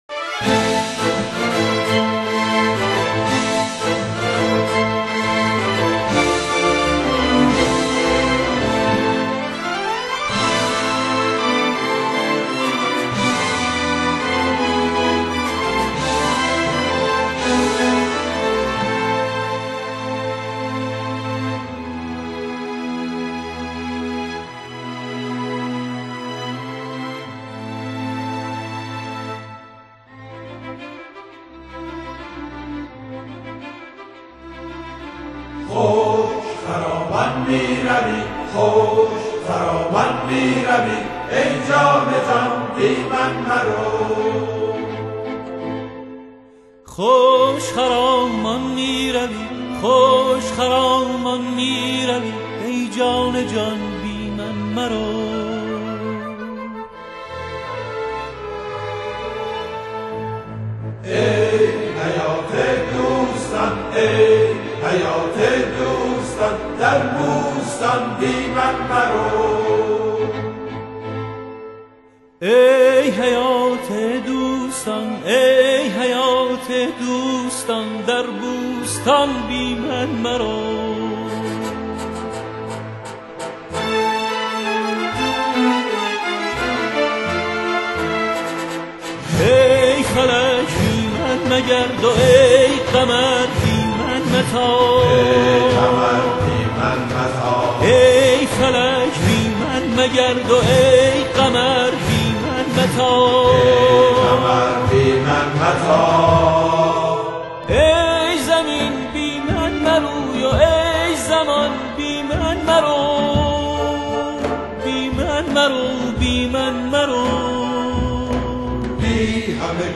تصنیف بازنویسی شده برای ارکستر سمفونیک